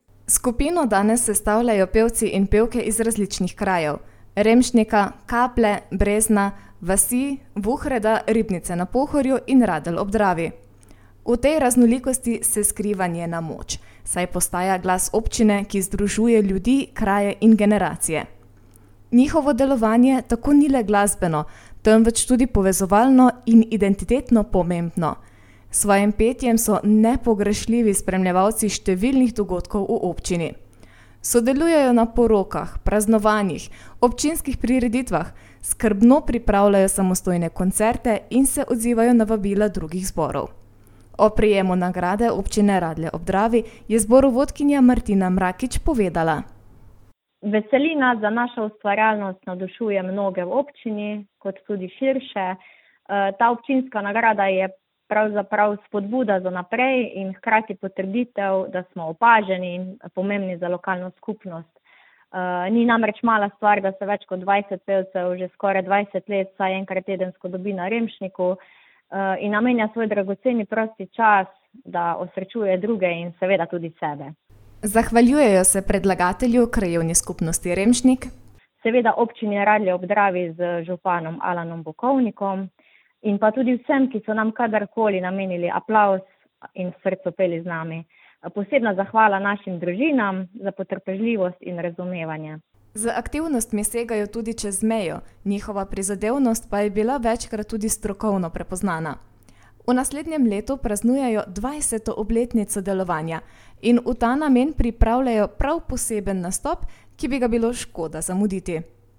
Lokalne novice | Koroški radio - ritem Koroške